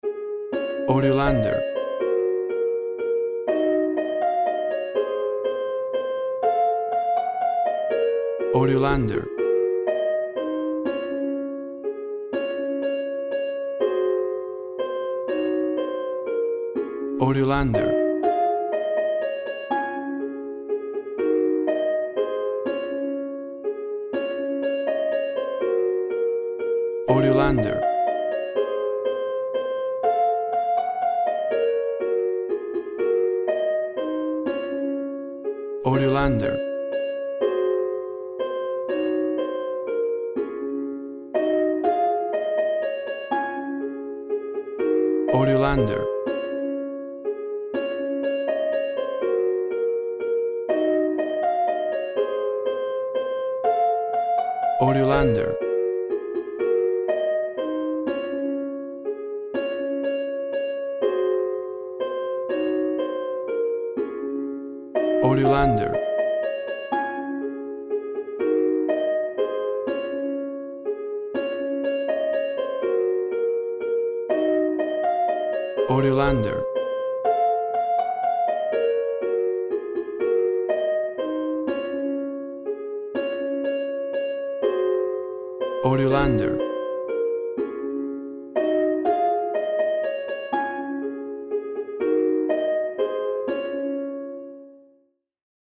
A traditional harp rendition
Full of happy joyful festive sounds and holiday feeling!.
WAV Sample Rate 16-Bit Stereo, 44.1 kHz
Tempo (BPM) 120